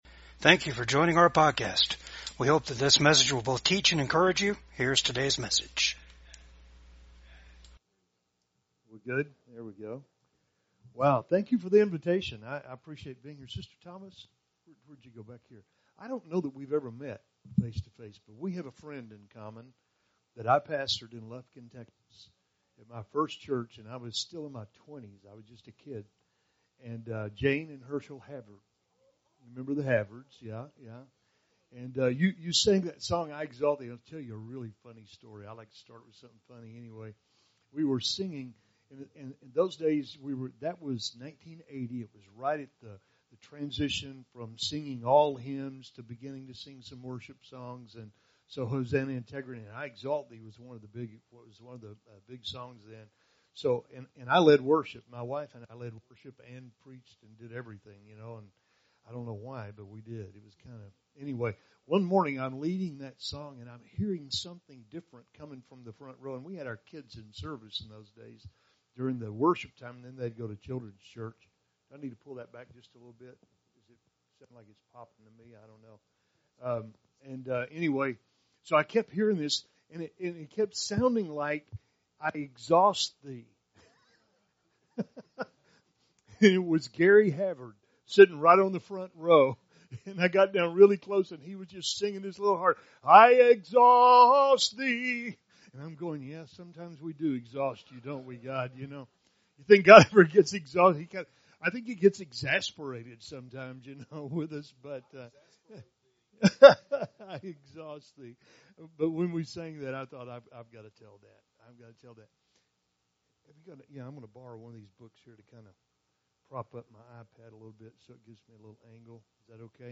Mark 5:21-34 Service Type: VCAG WEDNESDAY SERVICE FAITH IN GOD MAKES IT POSSIBLE TO GET THROUGH WHATEVER YOU ARE GOING THROUGH.